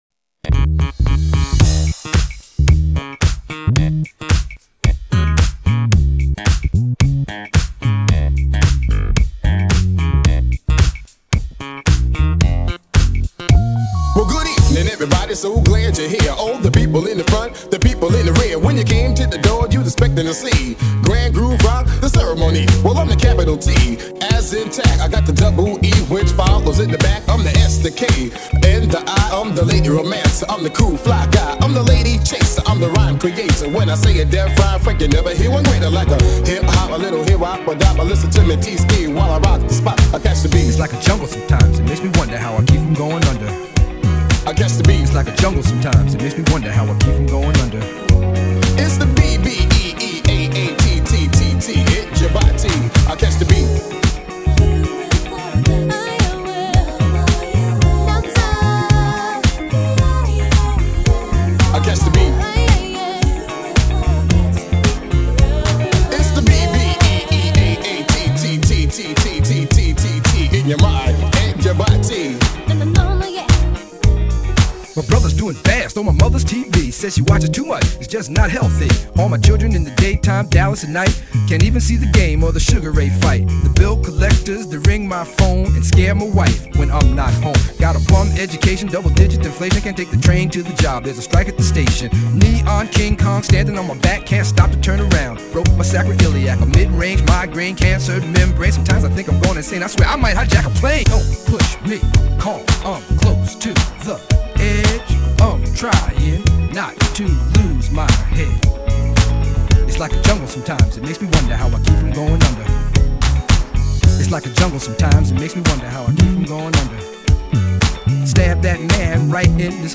2026 Audio English Funk Video